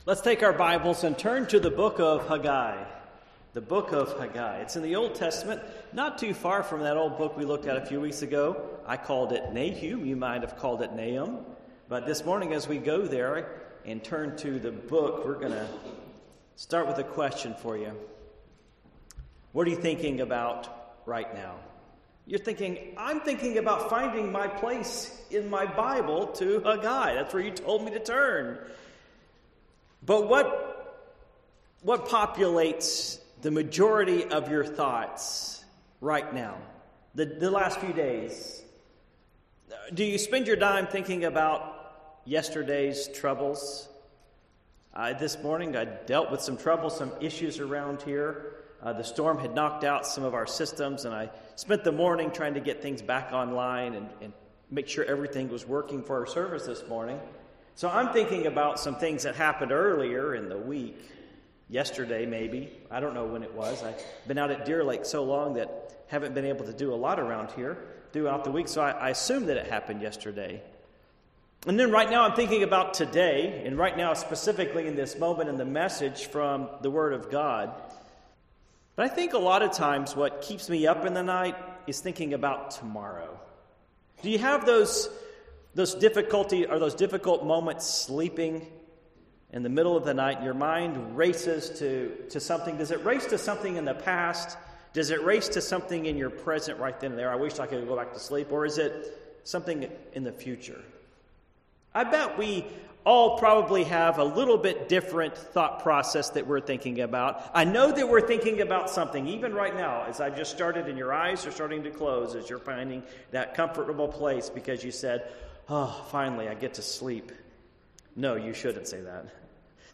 Passage: Haggai 2:1-9 Service Type: Morning Worship